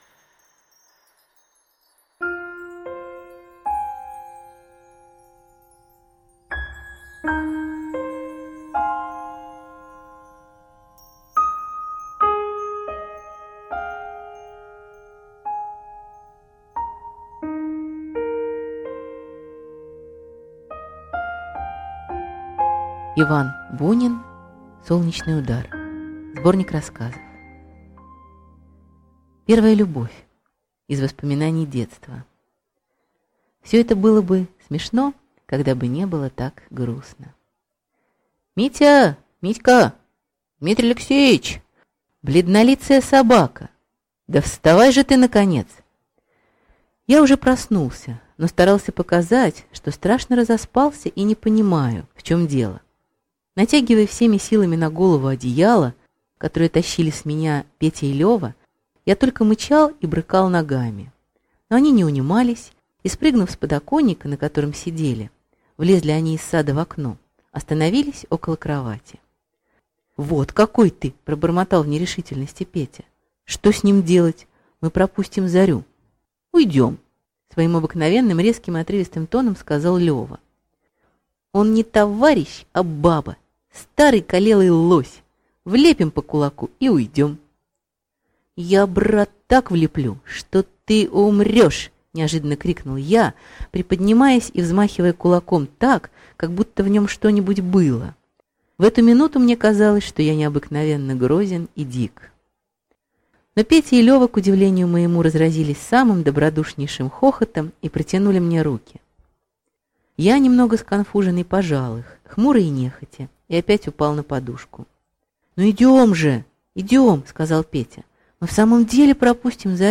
Аудиокнига Солнечный удар (сборник) | Библиотека аудиокниг